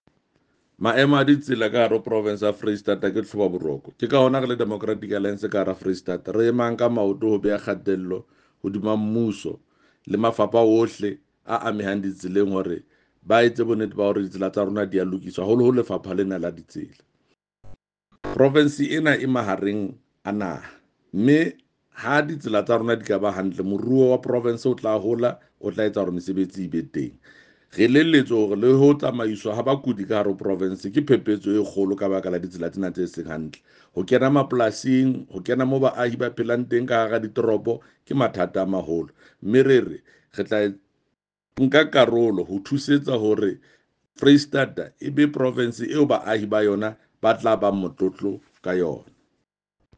Sesotho soundbite by Jafta Mokoena MPL with images here, here, here and here